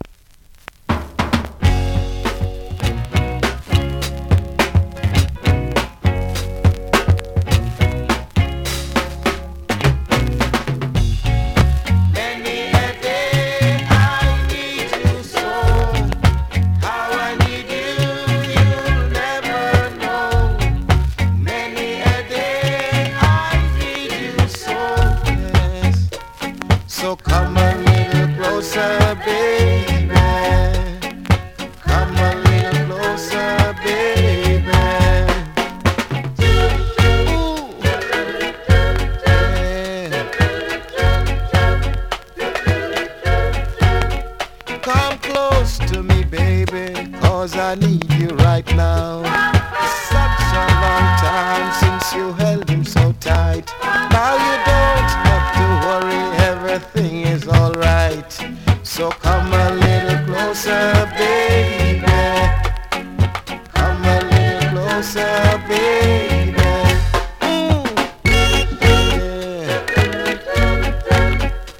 2014 NEW IN!!DANCEHALL!!
スリキズ、ノイズそこそこありますが